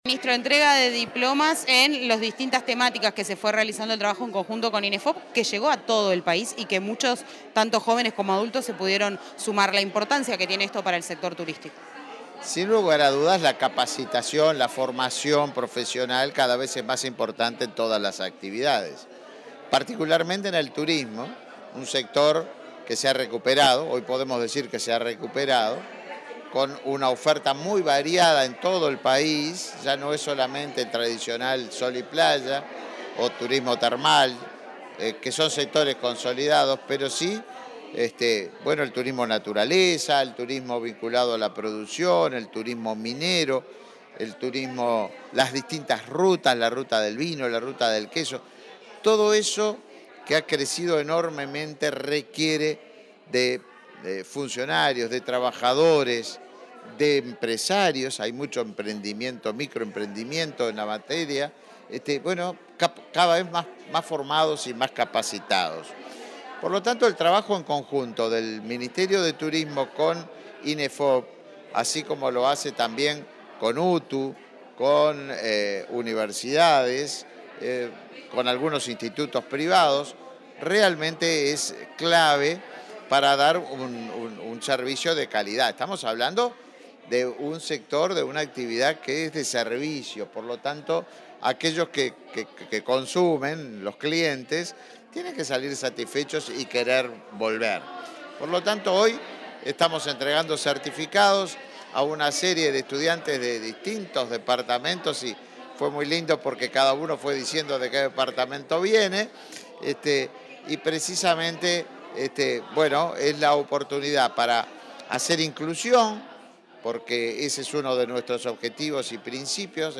Declaraciones del ministro de Turismo, Tabaré Viera
Declaraciones del ministro de Turismo, Tabaré Viera 14/04/2023 Compartir Facebook X Copiar enlace WhatsApp LinkedIn El Ministerio de Turismo y el Instituto Nacional de Empleo y Formación Profesional (Inefop) entregaron, el jueves 13, diplomas a los distintos sectores que recibieron capacitación en ese sector. El titular de la cartera, Tabaré Viera, dialogó con la prensa sobre la importancia de la temática.